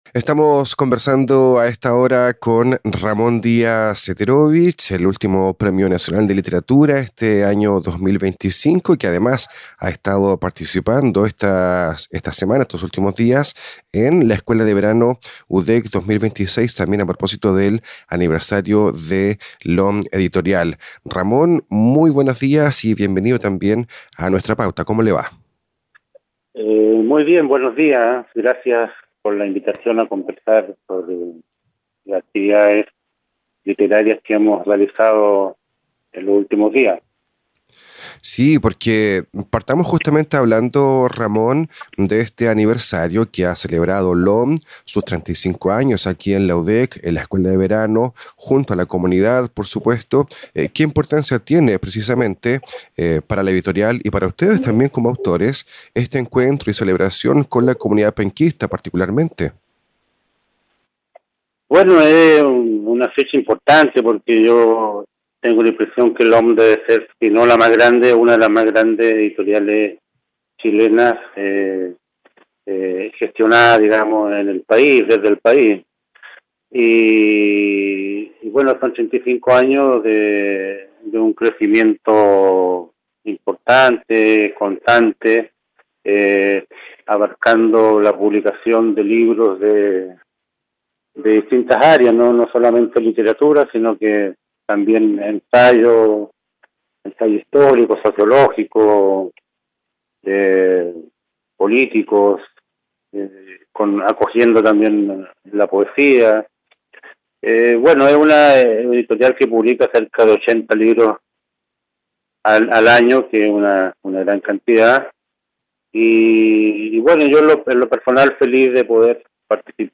En el marco de las actividades realizadas por el 35º aniversario de LOM Ediciones, el Premio Nacional de Literatura 2025, Ramón Díaz Eterovic, conversó con Nuestra Pauta sobre la editorial que ha publicado prácticamente toda su obra, el auge de la novela policial y la importancia de encontrarse con el público lector.